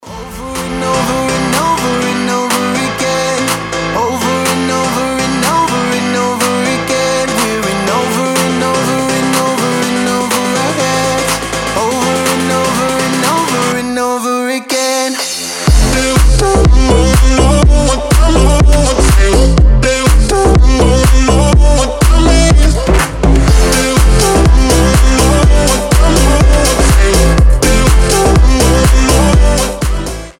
• Качество: 320, Stereo
мужской голос
Electronic
EDM
Стиль: piano house